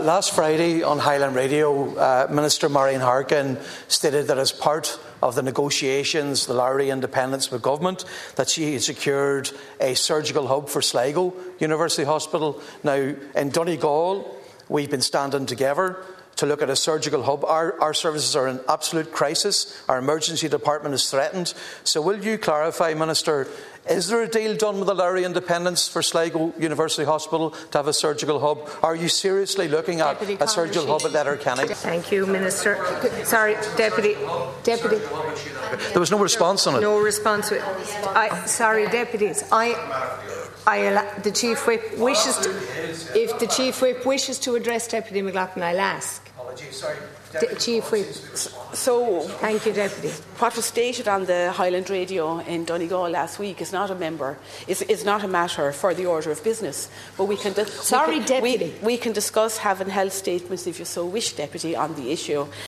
The issue was raised during discussions on the week’s Order of Business in the Dail this afternoon by Sinn Fein Whip and Donegal Deputy Padraig MacLochlainn, who referenced the assertion by Minister Marian Harkin on last Friday’s Nine til Noon Show that a specific commitment had been given………..